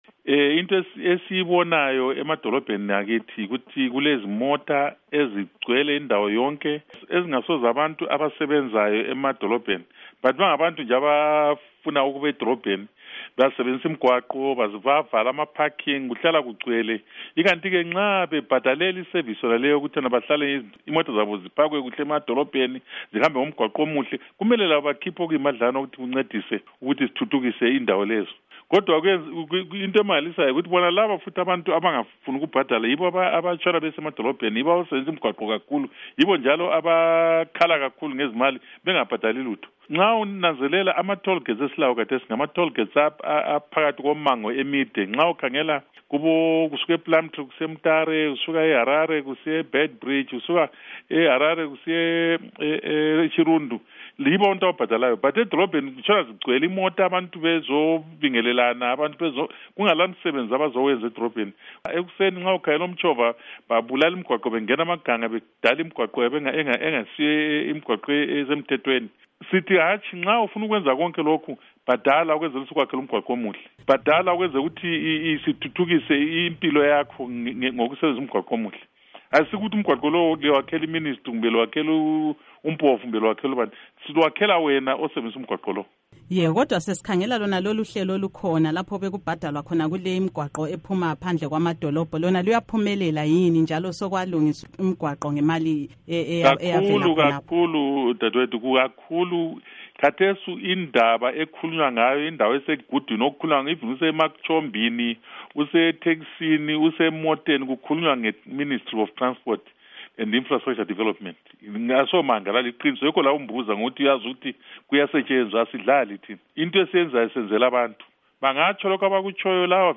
Ingxoxo LoMnu. Obert Mpofu